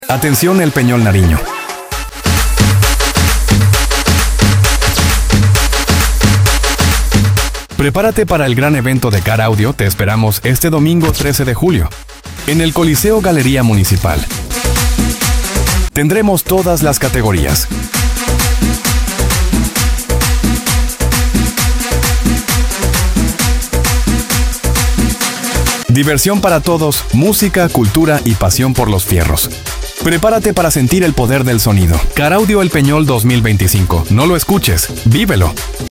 🎥 ¡Y tú serás parte de este momento ÉPICO! No te pierdas nuestro video oficial... 🚀 Cámaras encendidas, bajos al máximo y pasión en cada toma.